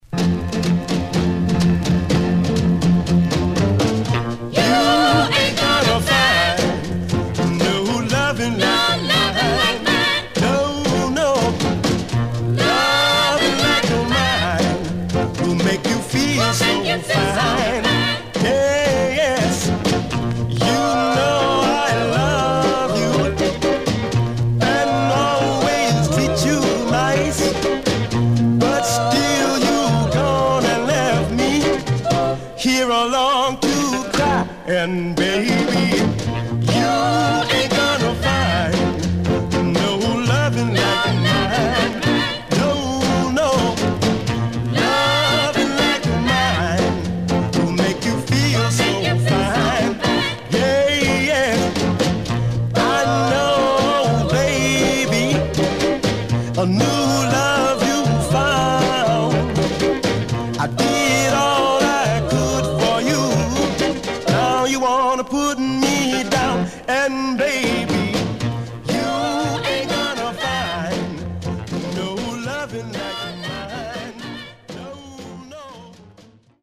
Stereo/mono Mono
Soul